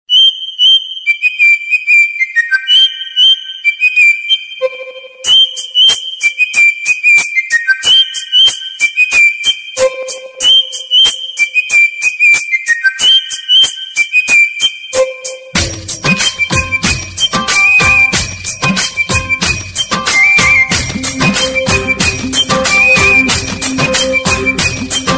Categoria Animali